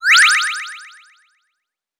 CartoonGamesSoundEffects
Magic_v1_wav.wav